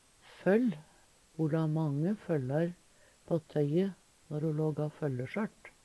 føll fold, brett Eintal ubunde Eintal bunde Fleirtal ubunde Fleirtal bunde føll føll`n føllar føllan Eksempel på bruk Ho la mange føllar på tøye når ho lågå føllesjørt. See also følle (Veggli) Hør på dette ordet Ordklasse: Substantiv hankjønn Kategori: Tekstilhandverk Attende til søk